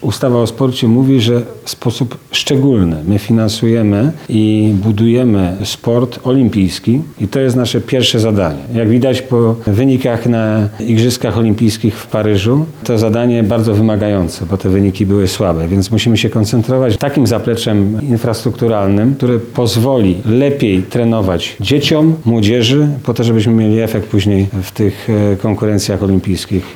– Samorządy będą mogły zgłaszać swoje projekty – mówił podczas spotkania z samorządami Lubelszczyzny wiceminister Sportu i Turystyki Ireneusz Raś.